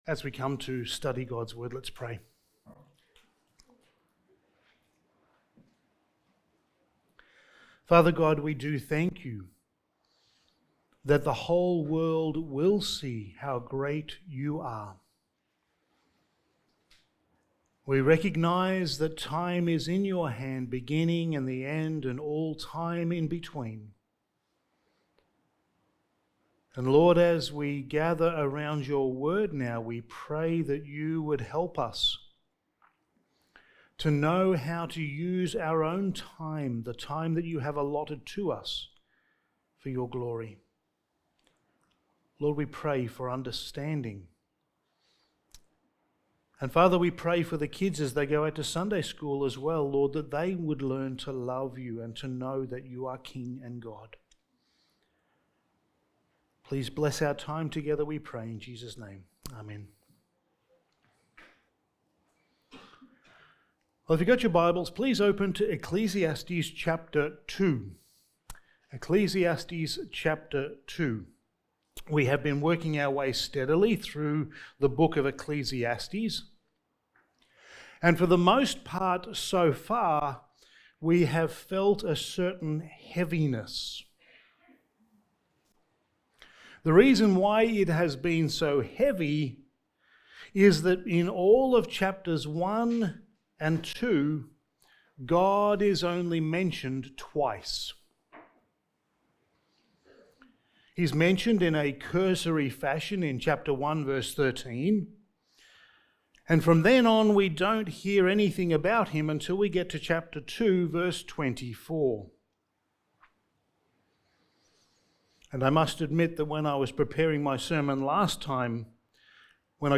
Studies in the Book of Ecclesiastes Sermon 6: A Biblical Study of Time
Service Type: Sunday Morning